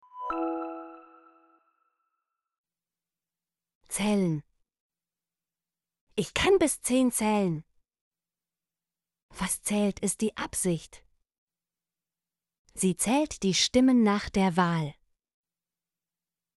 zählen - Example Sentences & Pronunciation, German Frequency List